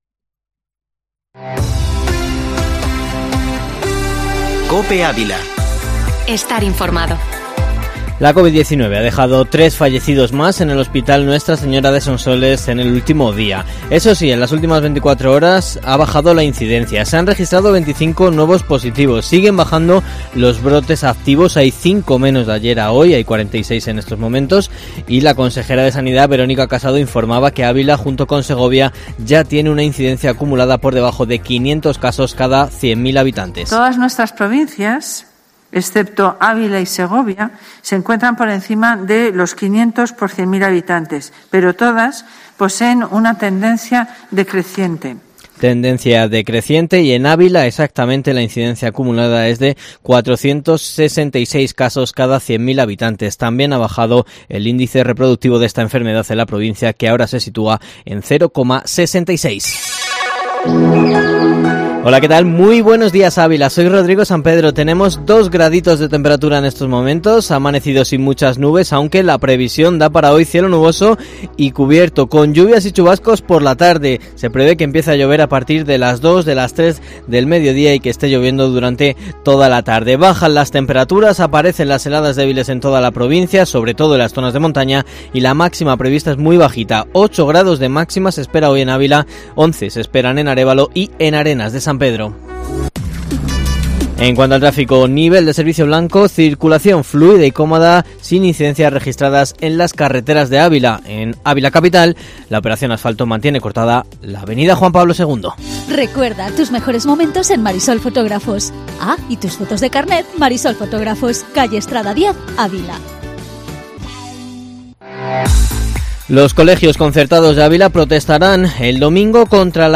Informativo matinal Herrera en COPE Ávila 25/11/2020